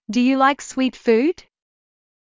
ﾄﾞｩ ﾕｳ ﾗｲｸ ｽｳｨｰﾄ ﾌｰﾄﾞ